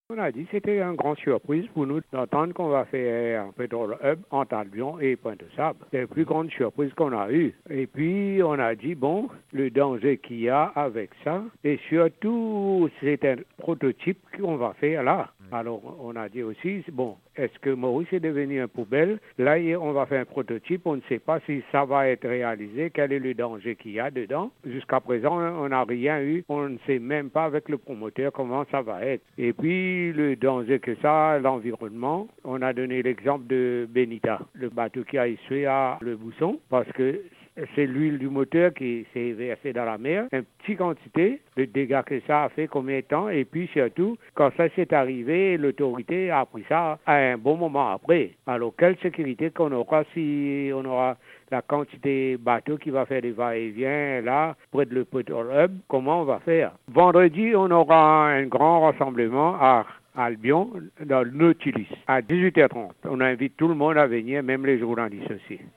Cette plateforme qui regroupe les habitants de la côte ouest a tenu une conférence de presse, jeudi 10 novembre au centre social Marie Reine de la paix, à Port-Louis.